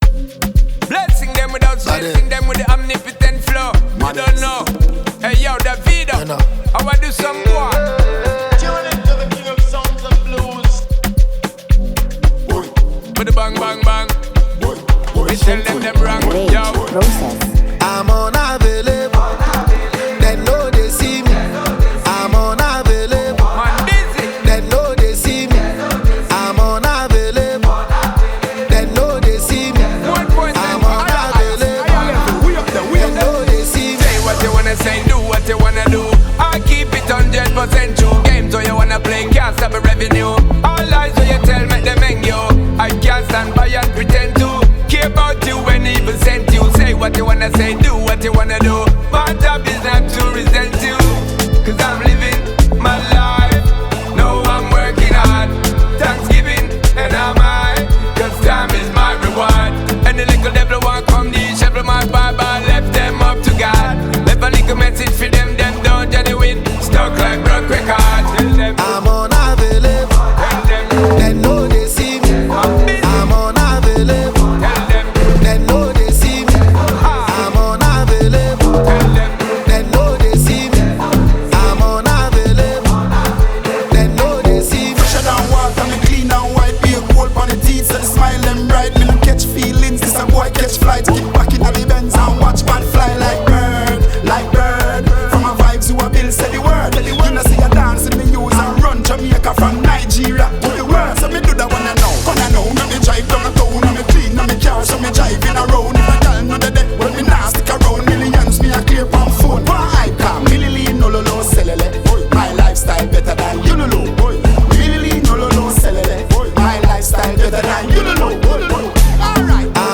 an established Nigerian afrobeat maestro
remix version